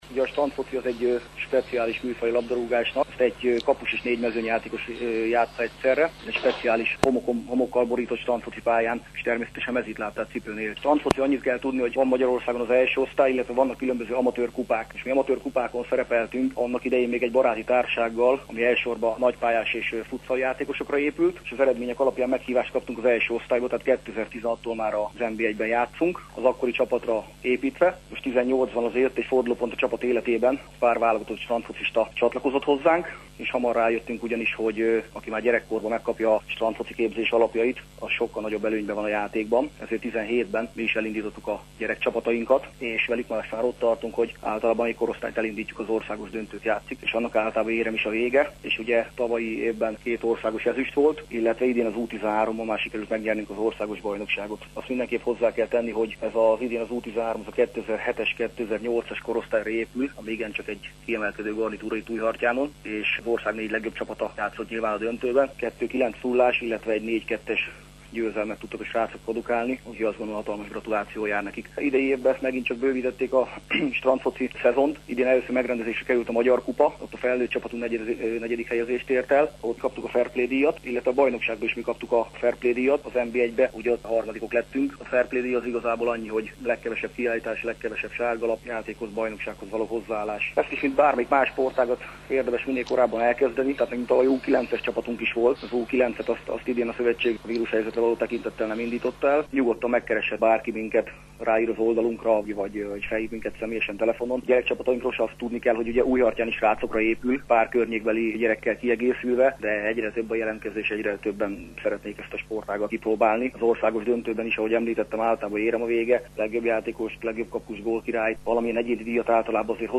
az anyatejes táplálás fontosságáról beszélt rádiónkban.